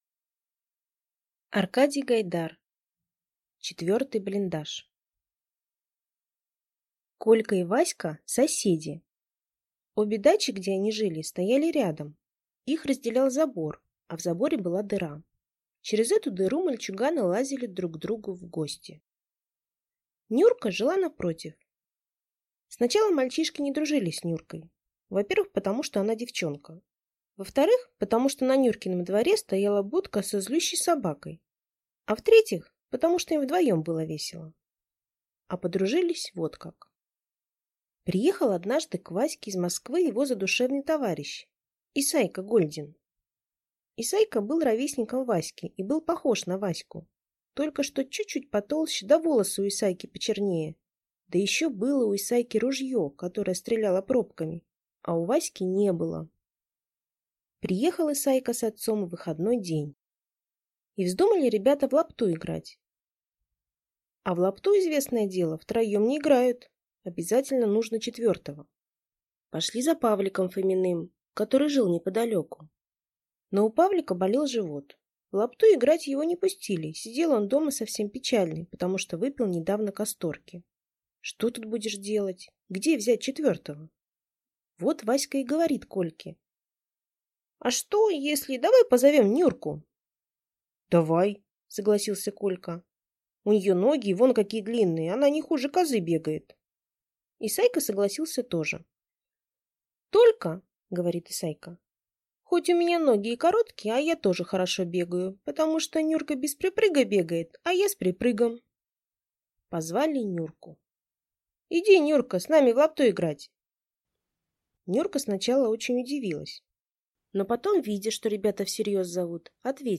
Аудиокнига Четвертый блиндаж | Библиотека аудиокниг